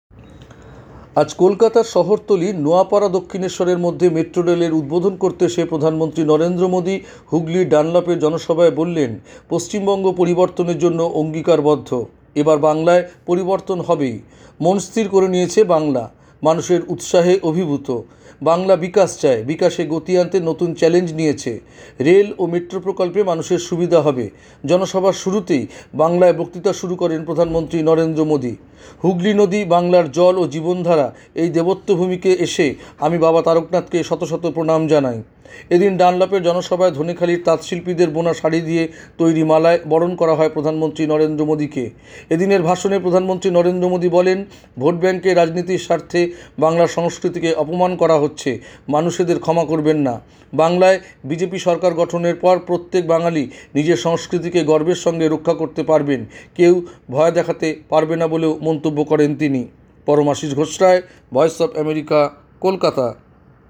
পশ্চিমবঙ্গ পরিবর্তনের জন্য অঙ্গীকারবদ্ধ, হুগলির জনসভায় মোদি